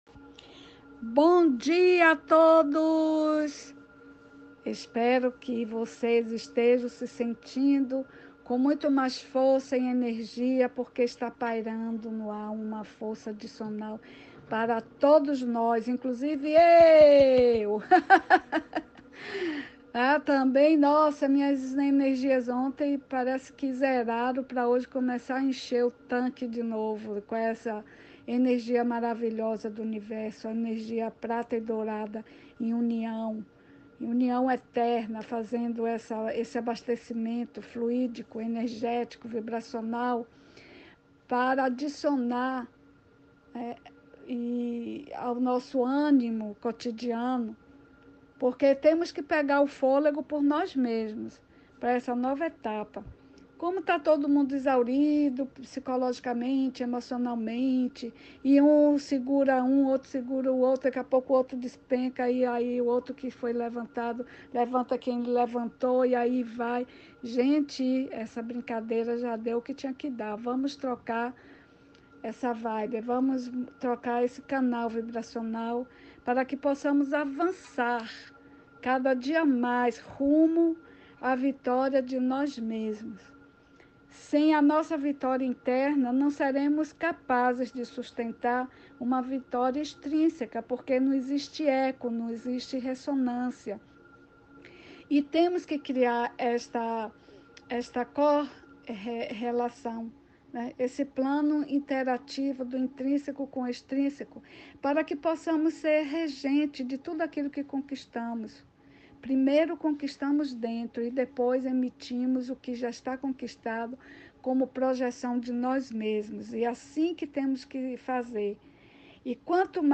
Fala
Salvador-BA